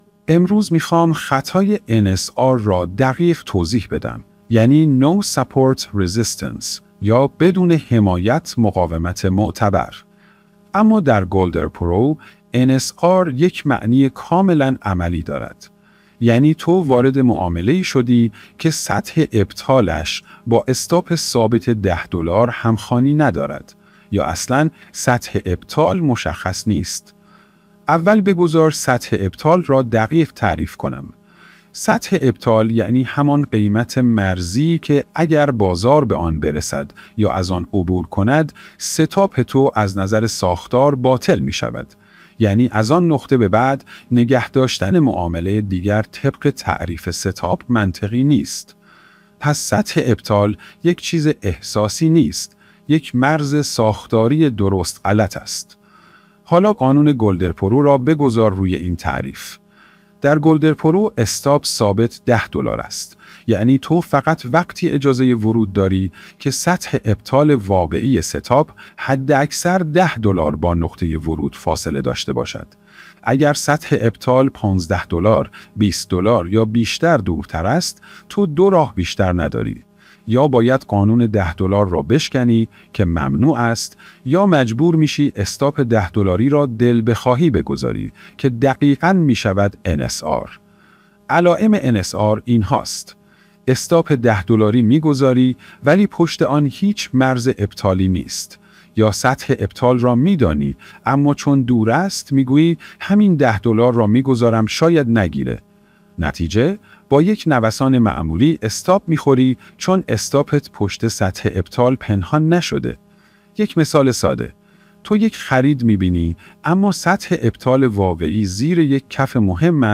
نسخهٔ صوتی آموزش